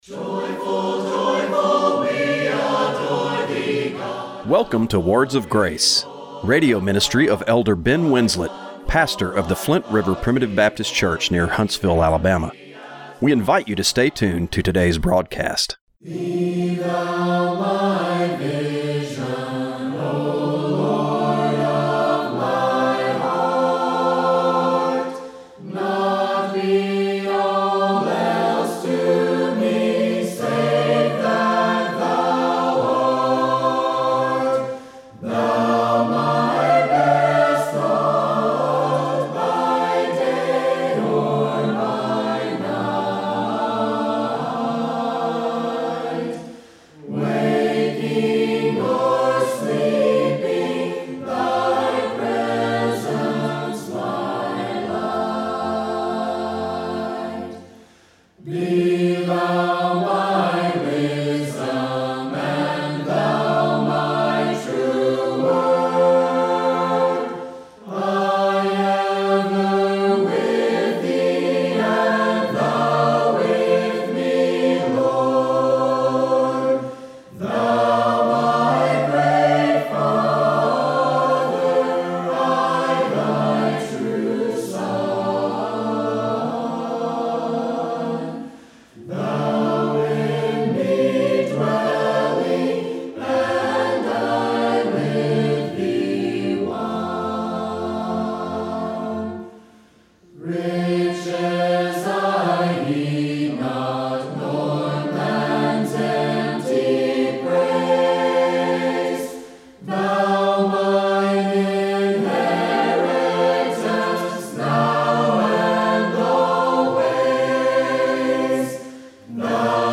Radio broadcast for July 20, 2025.